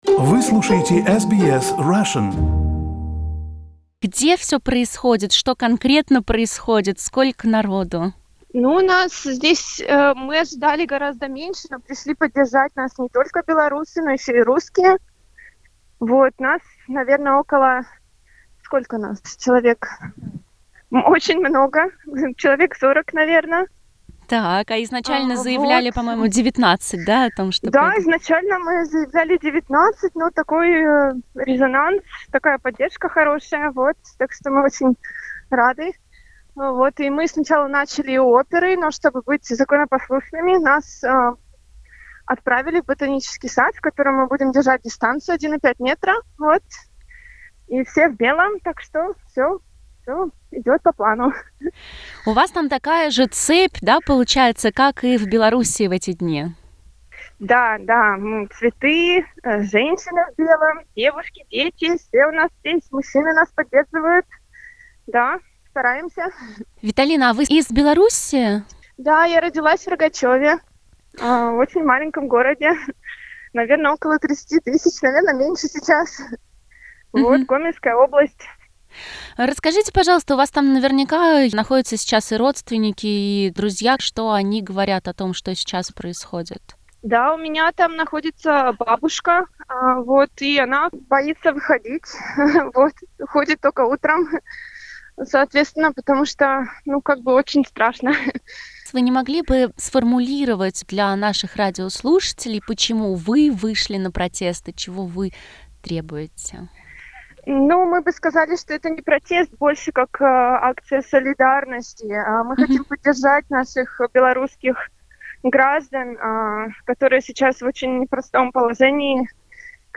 На мирную акцию в поддержку Беларуси в Сиднее пришли женщины в белых одеждах с цветами, некоторые из них держали плакаты. SBS Russian публикует интервью с участницей, фото и видео с места событий.